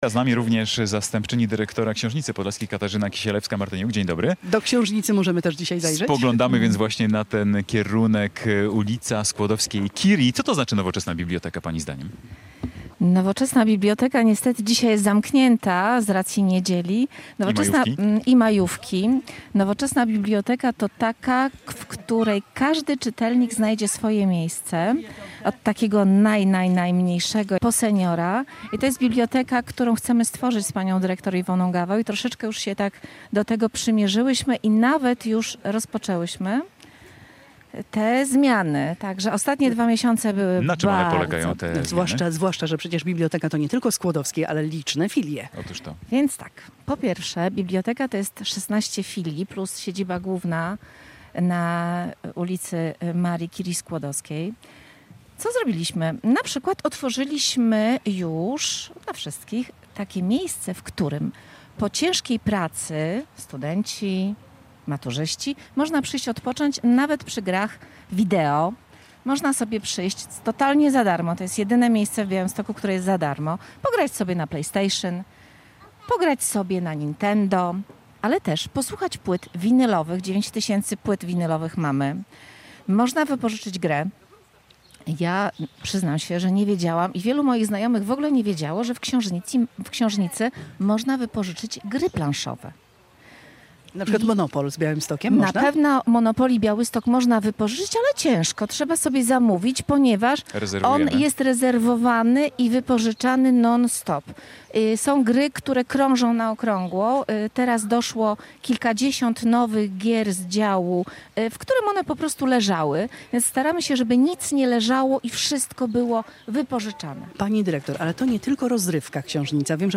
Polskie Radio Białystok w niedzielę otworzyło mobilne studio przy Ratuszu.